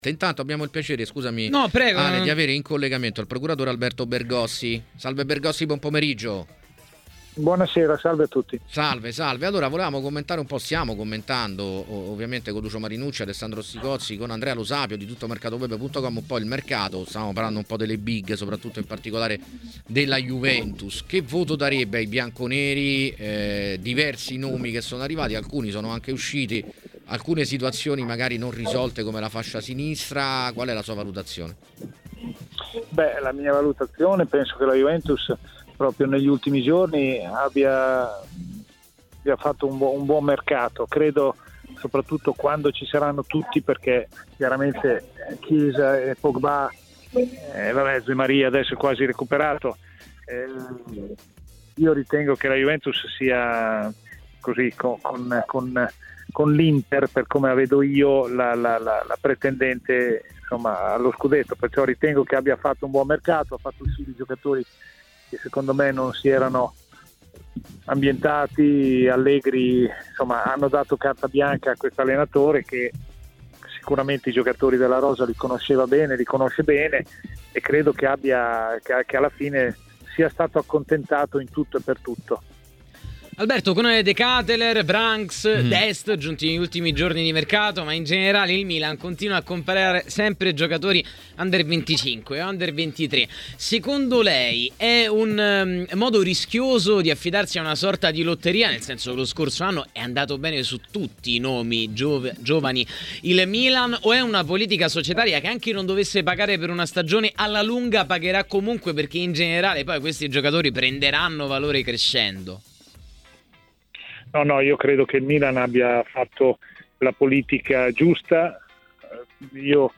è intervenuto ai microfoni di "A Tutto Mercato", nel pomeriggio di TMW Radio: Il Milan ha puntato sui giovani anche quest'anno: che ne pensa?